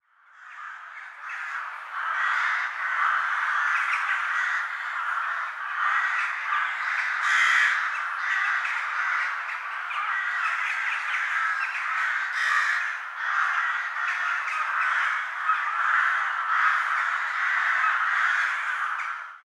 4aef571f59 Divergent / mods / NPCs Die in Emissions / gamedata / sounds / ambient / blowout / cut / closemedium.ogg 858 KiB (Stored with Git LFS) Raw History